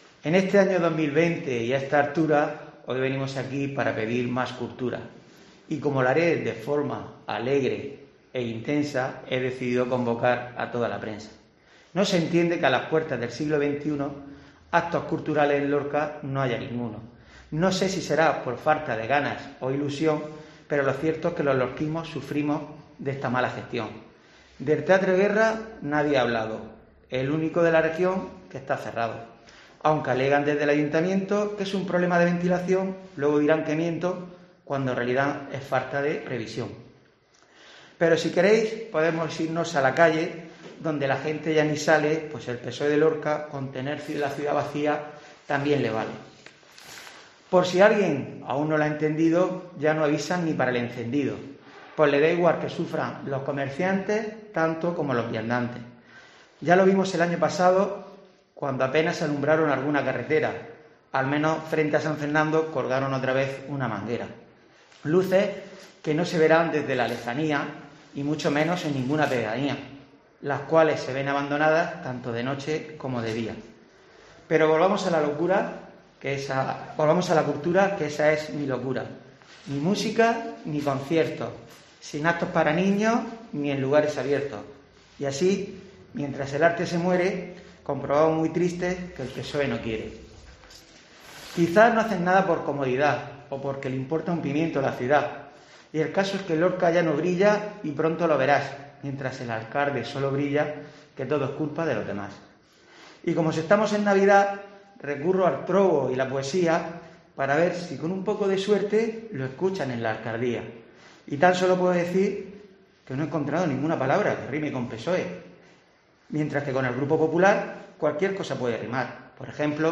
Francisco Javier Martínez, edil del PP denucia cultural en trovo